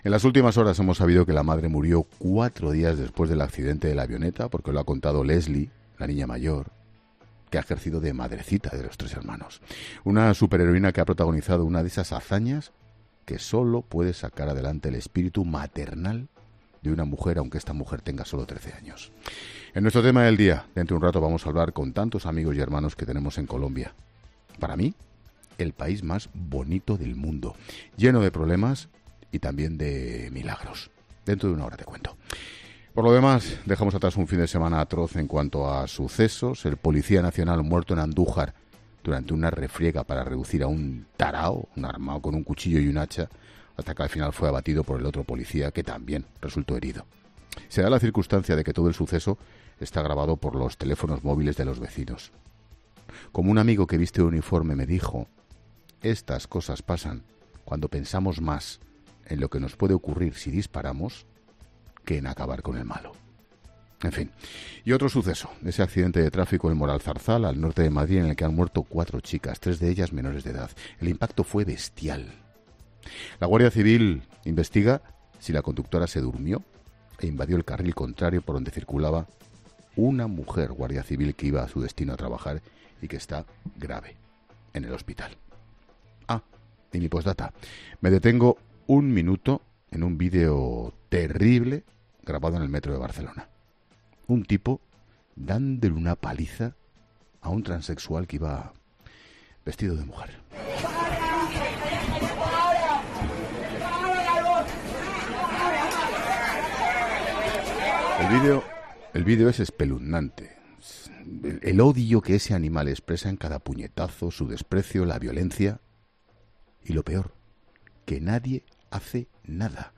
Así, durante el monólogo de este lunes en el programa, Ángel Expósito ha querido dedicar unas líneas a la página de sucesos de los últimos días en nuestro país.